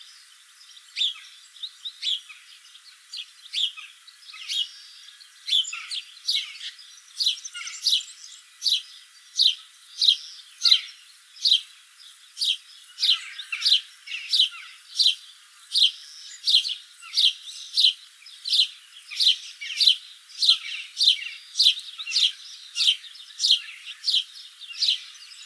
PASSER DOMESTICUS ITALIAE - ITALIAN SPARROW - PASSERA D'ITALIA
E 11° 02' - ALTITUDE: +20 m. - VOCALIZATION TYPE: two different call types. - SEX/AGE: unknown - COMMENT: The recording was made with an automatic apparatus.
Background: Jackdaw calls.